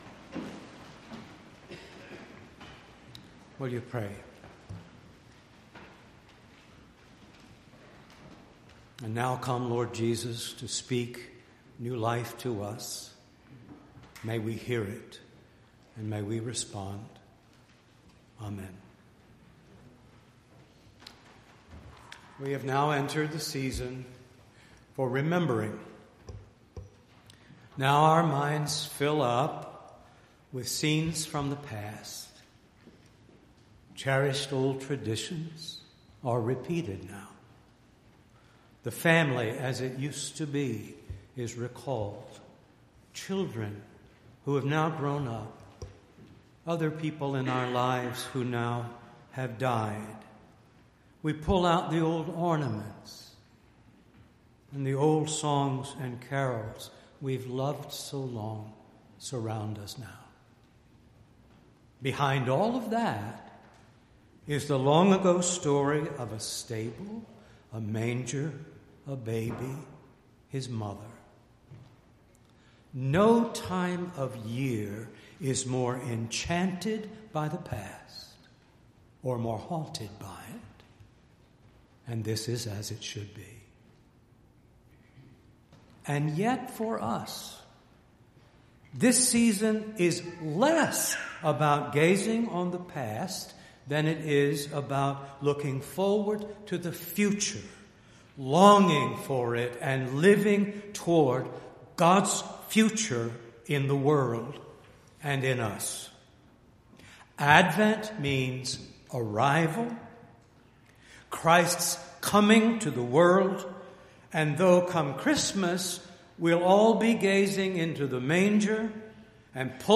12-2-18-sermon.mp3